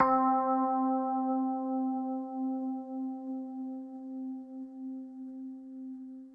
Rhodes - Hitz.wav